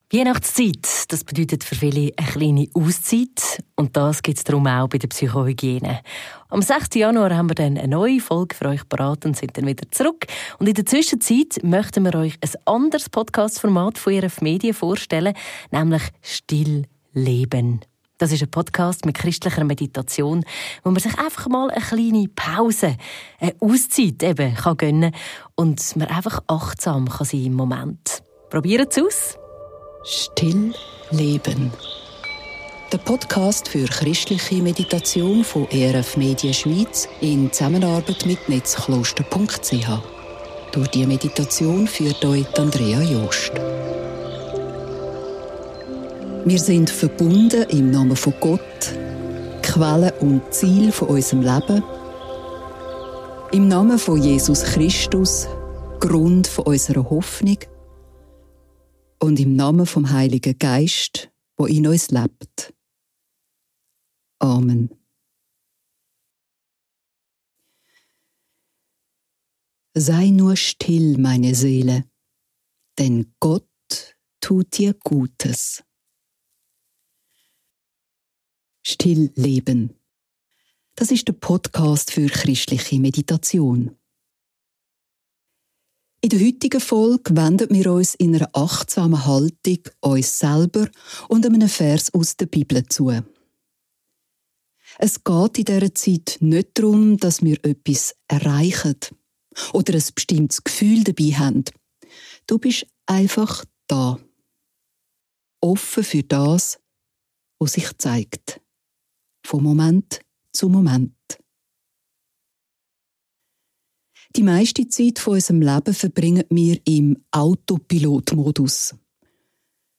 Ein Podcast mit christlicher Meditation, der euch eine Pause schenkt und dazu einlädt, achtsam im Moment zu verweilen.